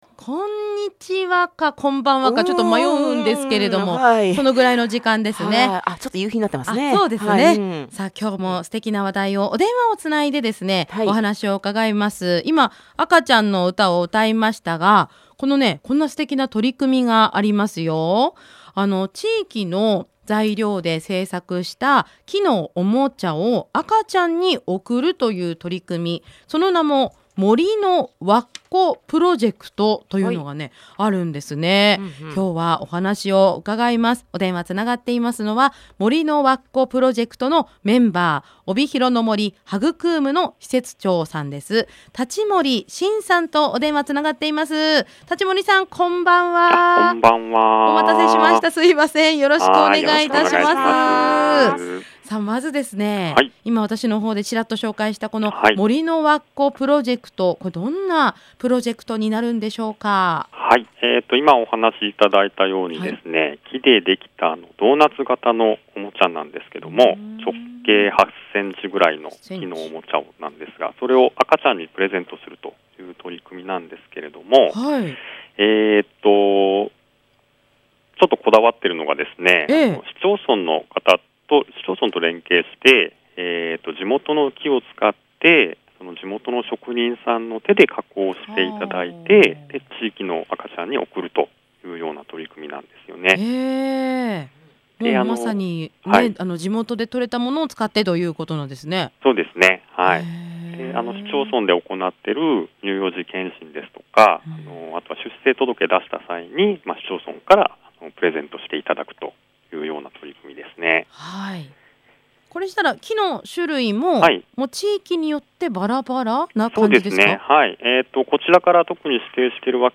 先日お知らせしました、FM WINGさんのラジオ番組が放映されました！